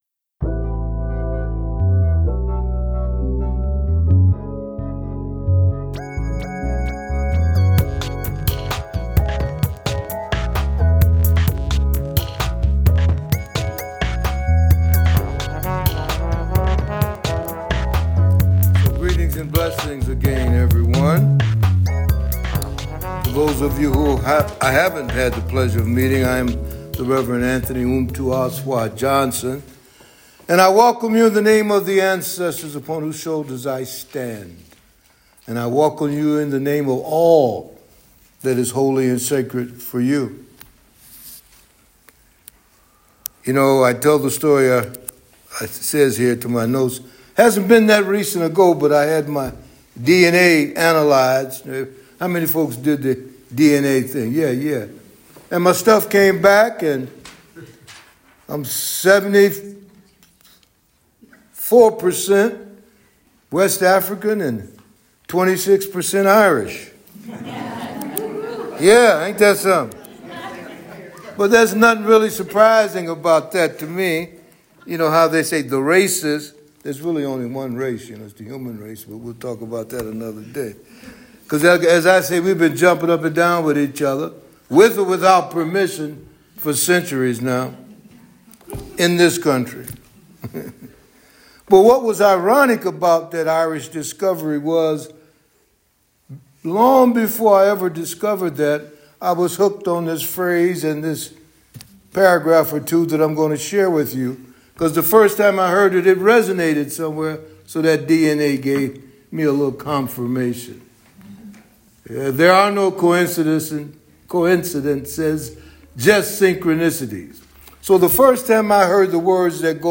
Click below to listen to the recorded sermon from today’s service.